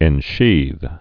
(ĕn-shēth)